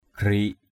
/ɡ͡ɣri:ʔ/ (d.) ghét, bụi bẩn = crasse. asah garik asH gr{K kì ghét, kì đất = gratter la crasse.